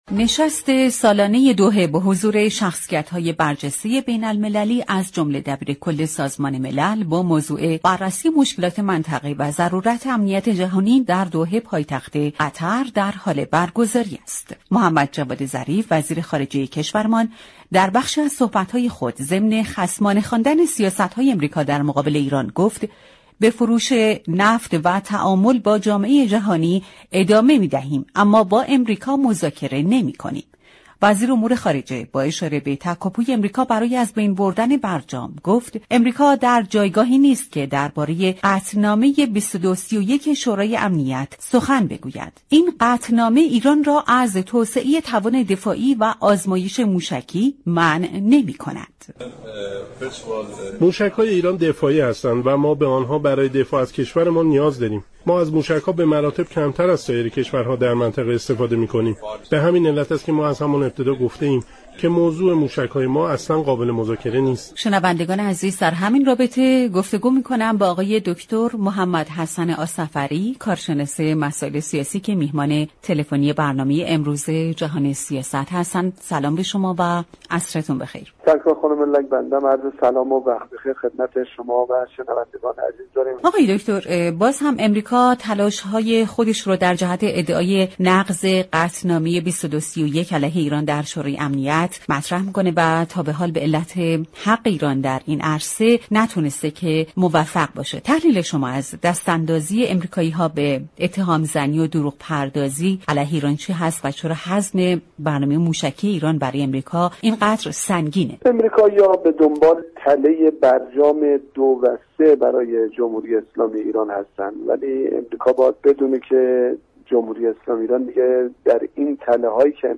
محمدحسن آصفری كارشناس مسائل سیاسی در برنامه جهان سیاست رادیو ایران